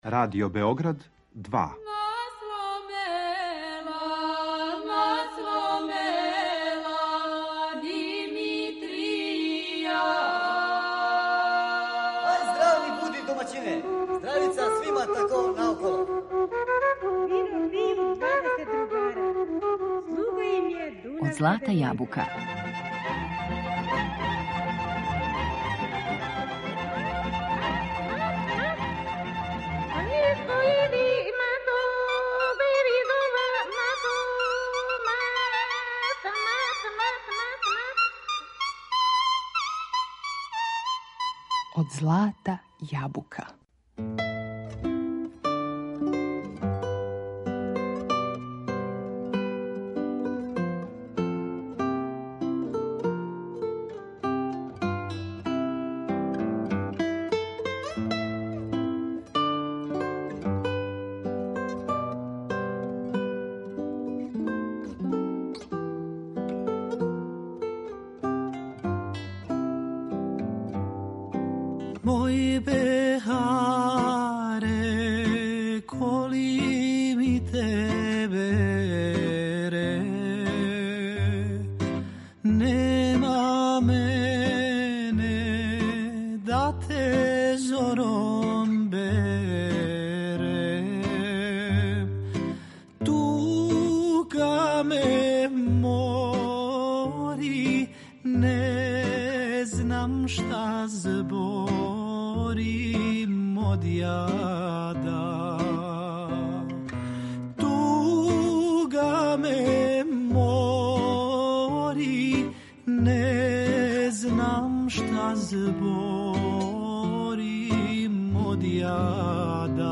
„Калем" је састав који изводи традиционалну музику Балкана одевену у ново рухо.